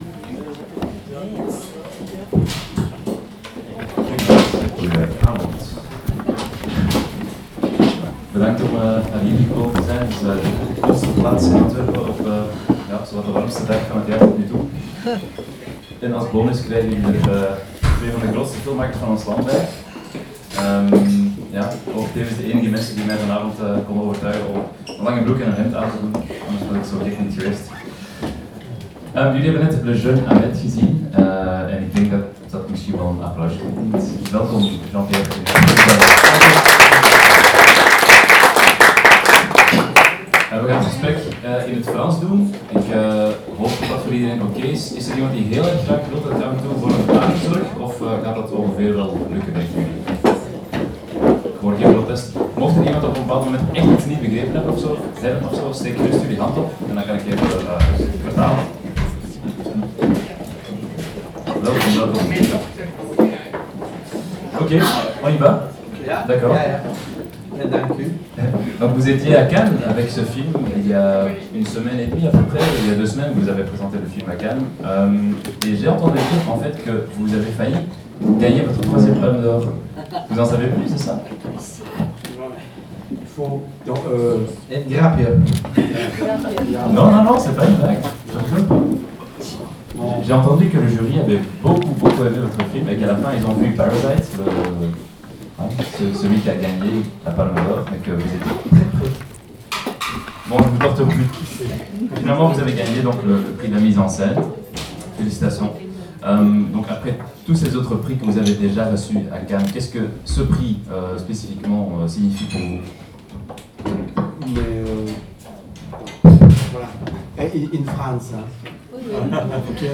Luc en Jean-Pierre Dardenne: Q&A na voorstelling van “Le jeune Ahmed” in Cinema Cartoon’s te Antwerpen
Tijdens hun promotoer in België hebben Jean-Pierre en Luc Dardenne “Le jeune Ahmed” voorgesteld in Cinema Cartoon’s te Antwerpen. Café Sous-Sol, de vertrouwde en polyvalente drankgelegenheid bekend tot ver buiten de stad en gelegen in de kelderverdieping van de bioscoop, was ingericht als een volwaardige evenementenzaal om beide topregisseurs te verwelkomen en het talrijk opgekomen publiek te ontvangen.